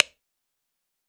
"Hovered Button Soud" From Mixkit